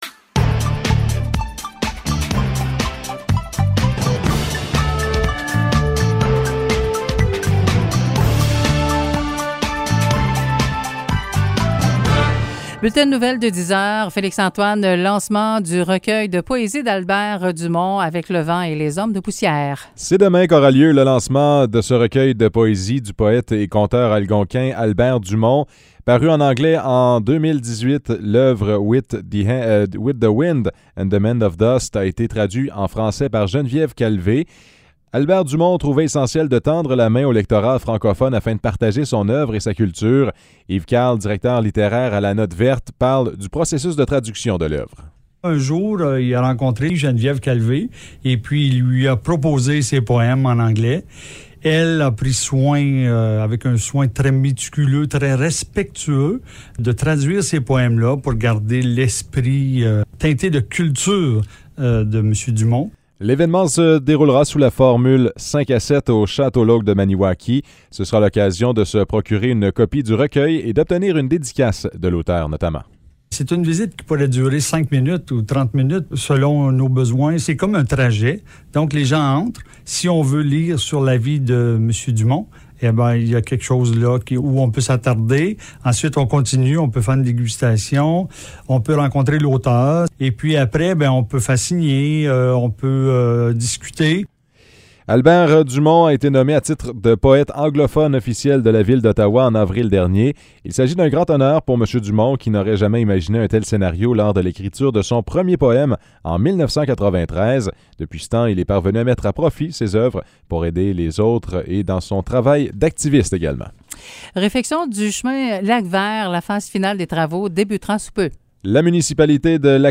Nouvelles locales - 30 septembre 2021 - 10 h